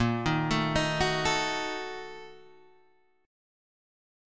A# Major 7th Suspended 4th Sharp 5th
A#M7sus4#5 chord {6 6 7 8 7 5} chord